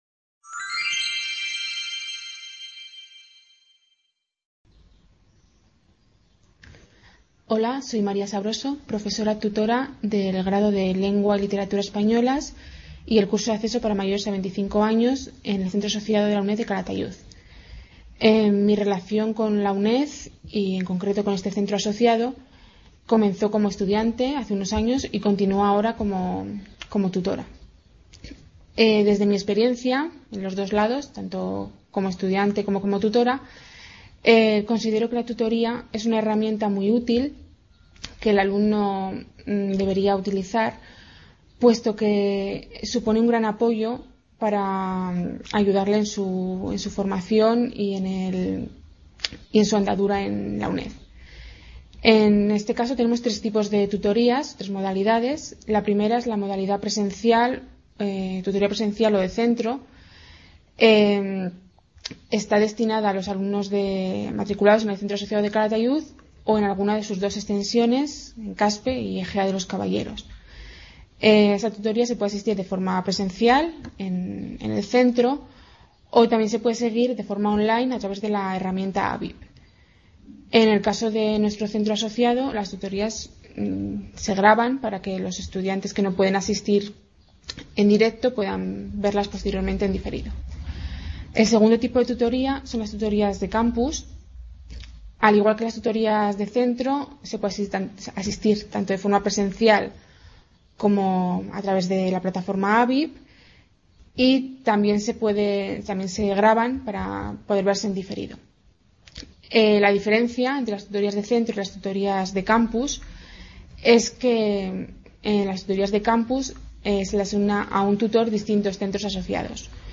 IUED- Plan de Acogida - Reflexiones de una profesora tutora sobre la tutoría en la UNED.
Video Clase